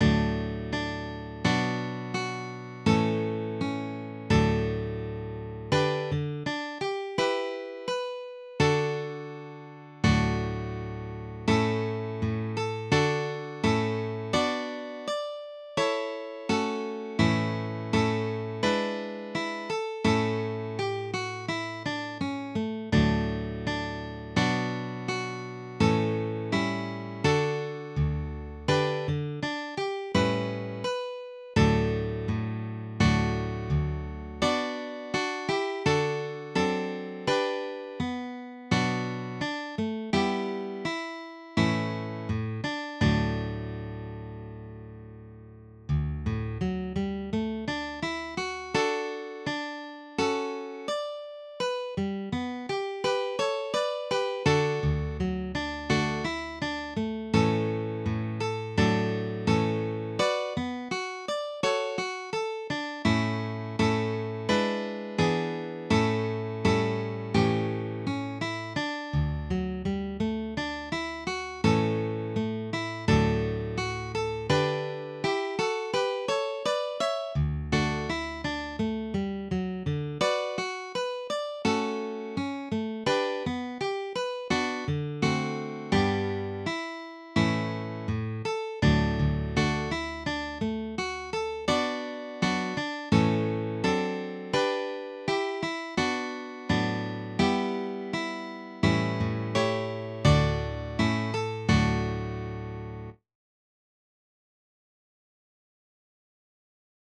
DIGITAL SHEET MUSIC - FLATPICK/PLECTRUM GUITAR SOLO
Sacred Music, Preludes, Graduals, and Offertories
Dropped D tuning